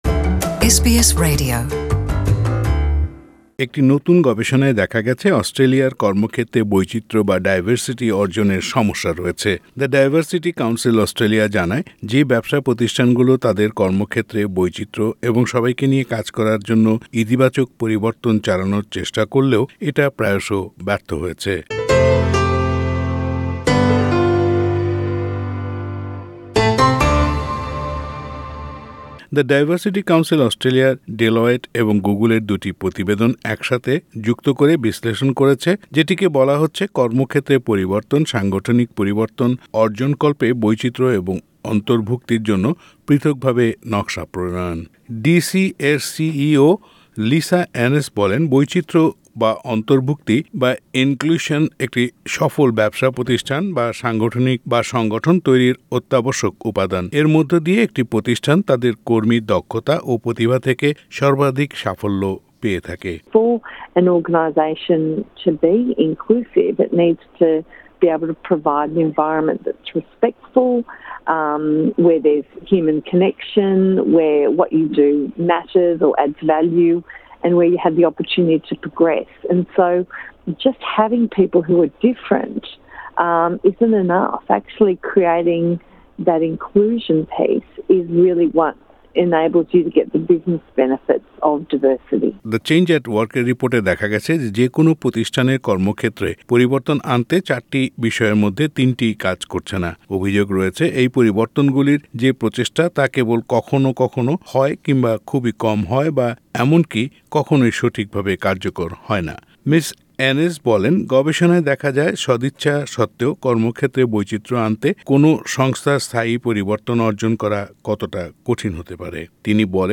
পুরো প্রতিবেদনটি বাংলায় শুনতে উপরের অডিও প্লেয়ারটিতে ক্লিক করুন।